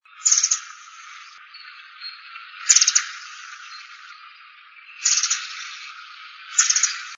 Piojito Común (Serpophaga subcristata)
Nombre en inglés: White-crested Tyrannulet
Localidad o área protegida: Reserva Ecológica Costanera Sur (RECS)
Condición: Silvestre
Certeza: Vocalización Grabada
RECS-Piojito-comun.mp3